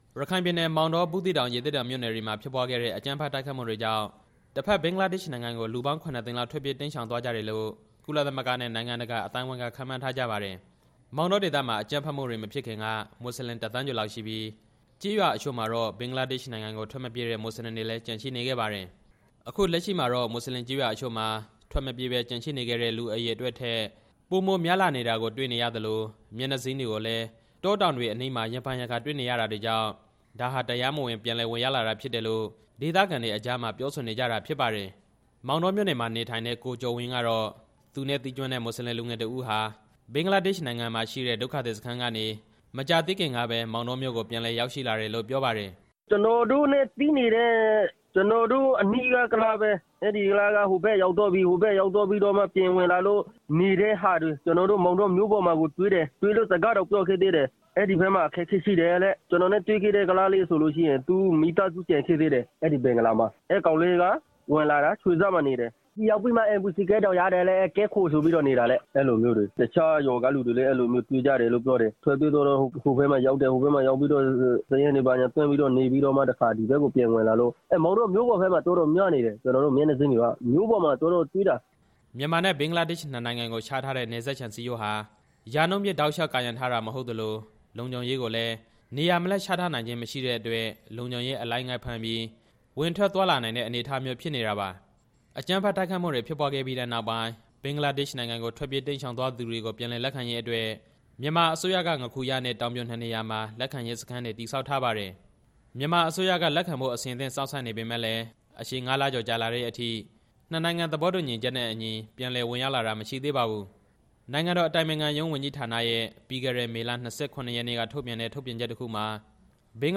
RFA သတင်းထောက်